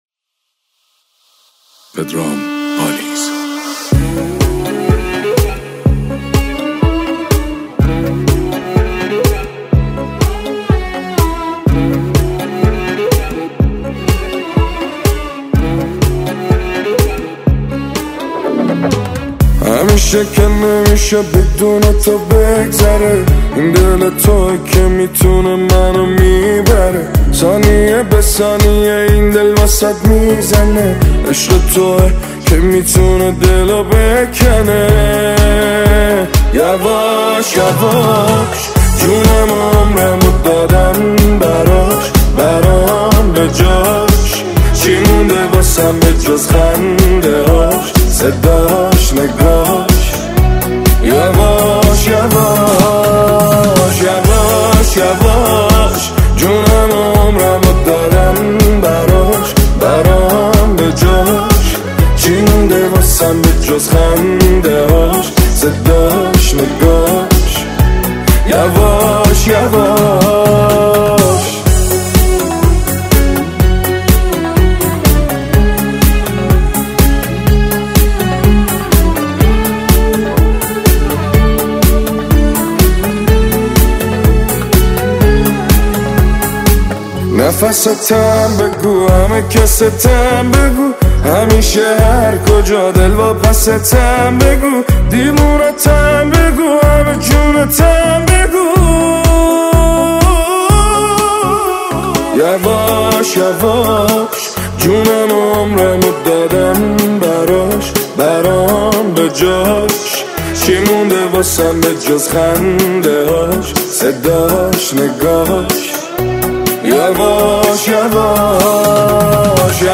ترانه شاد و زیبای جدید